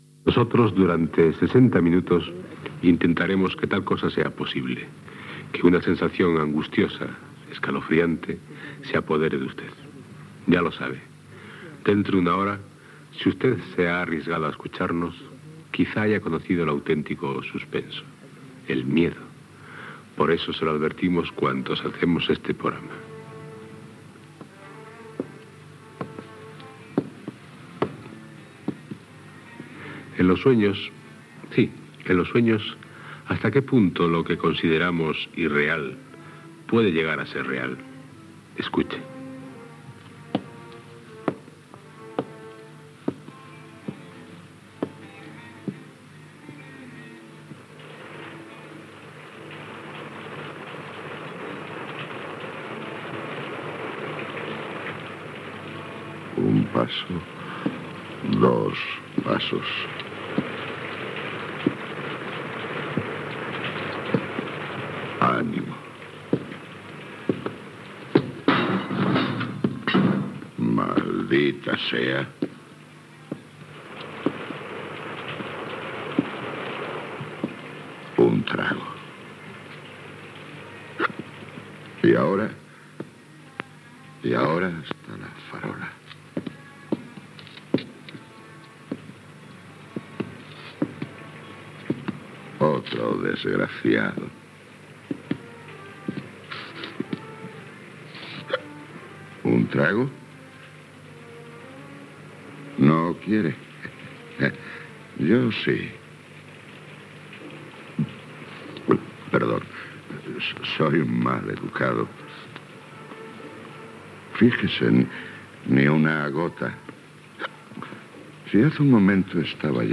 Una persona camina beguda. Careta de l'episodi. El protagonista està a casa seva amb la seva dona i un gat Gènere radiofònic Ficció